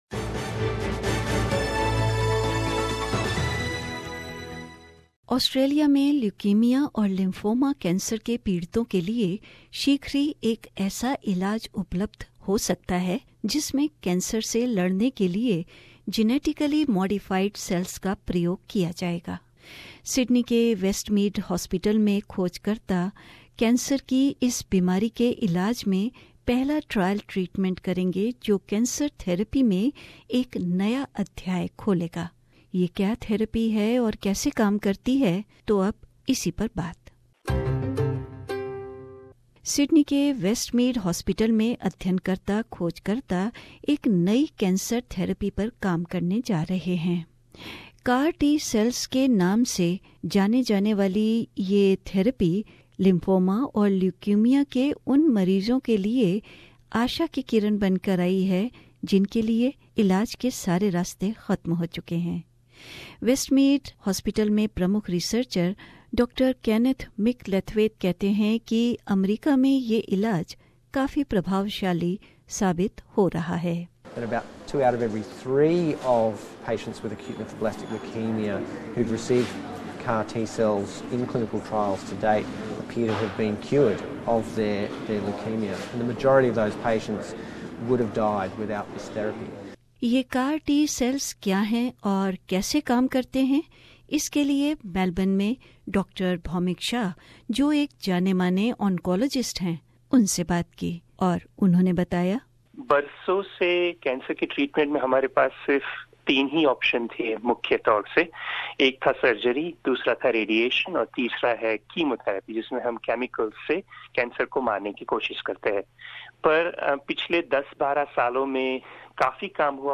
SBS Audio